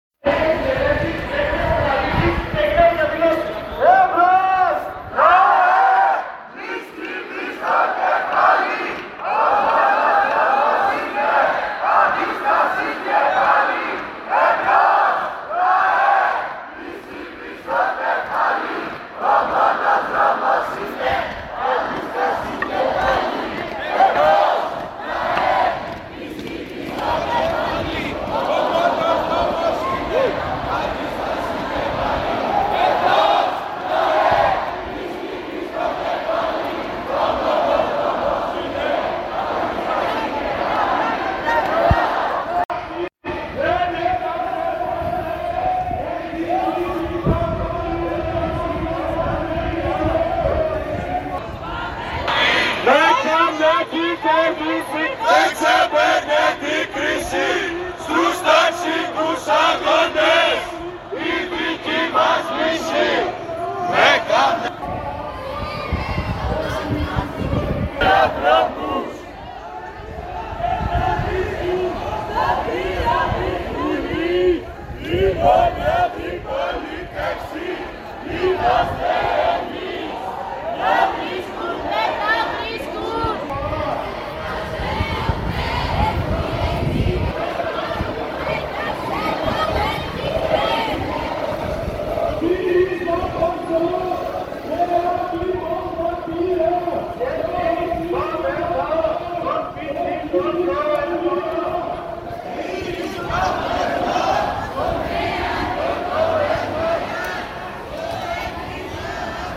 We can hear the protest chants of thousands of protesters in central Athens, calling for an investigation into the deaths of Greek citizens in a train accident due to missing security protocol and corruption. The protest took place March 8, 2023 at 16:55.